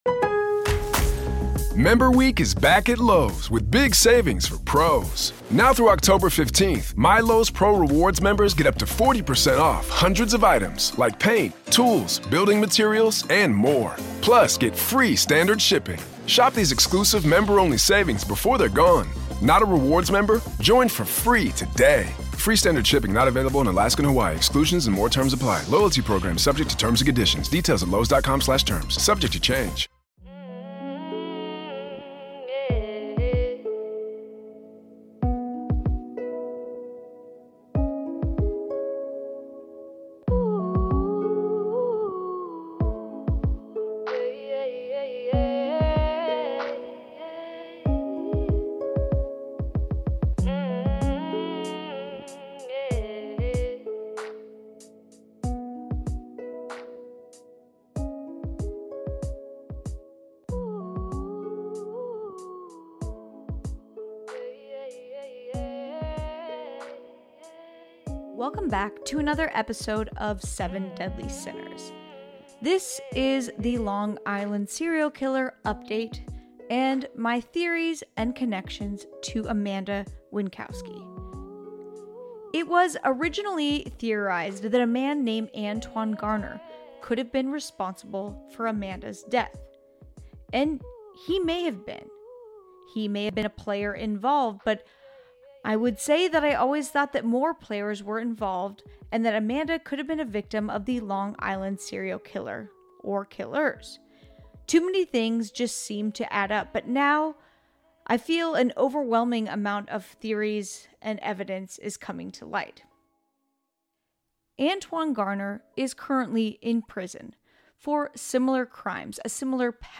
interviewing a victim and known associate of one the suspects